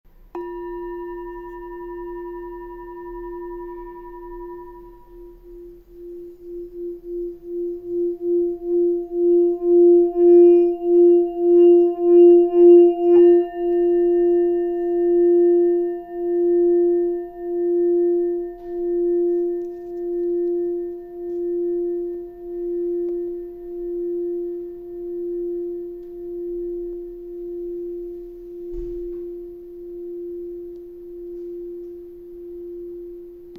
Bol tibétain traditionnel Full moon en 7 métaux. Fabriqué au Népal à la pleine lune. Note : Fa.